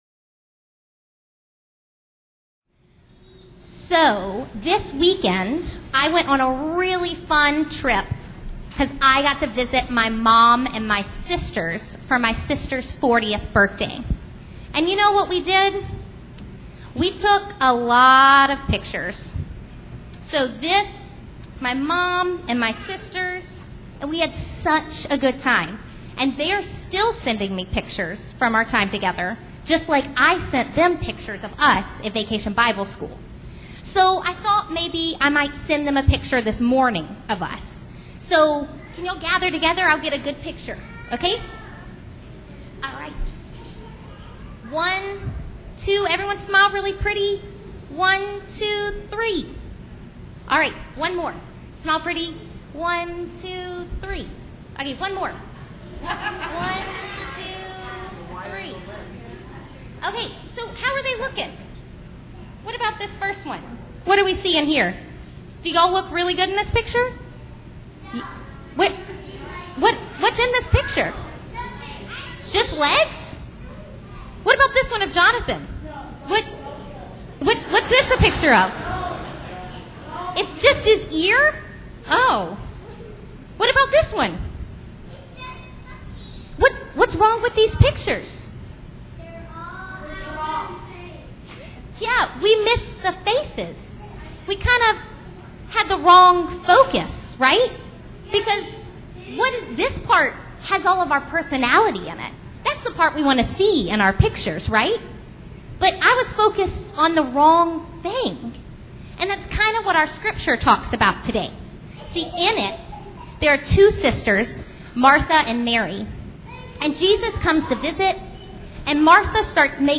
Children's Sermon